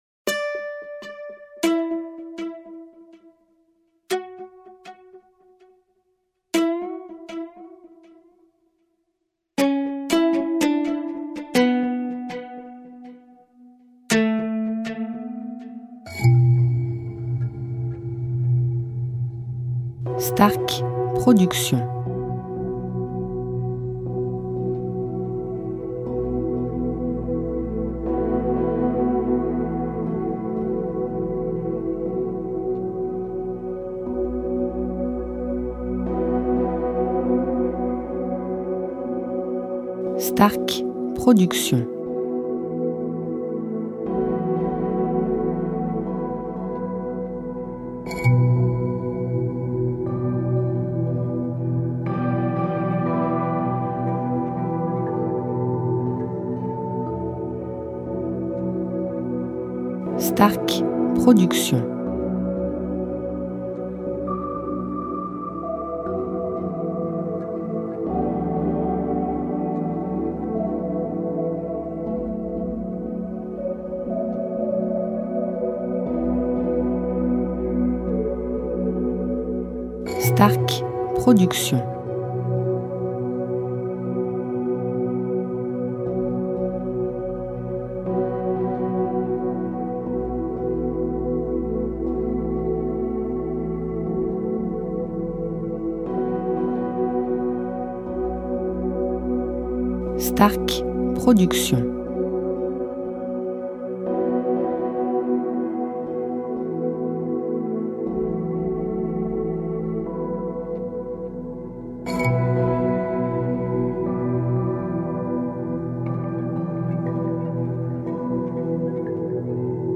style Asie